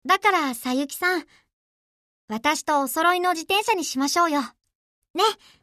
「ゆりりん」が、フルボイスでゲームになりました！！